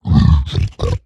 assets / minecraft / sounds / mob / hoglin / idle6.ogg